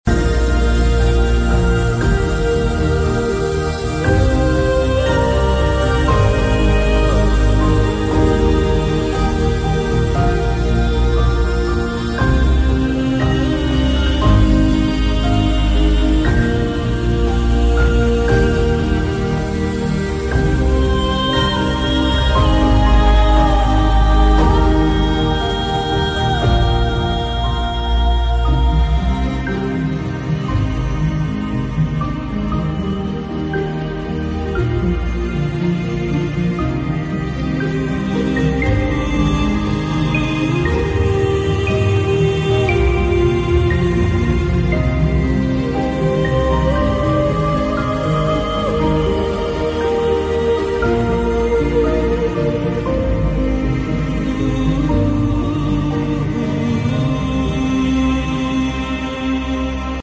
Epic Vocal Fantasy Music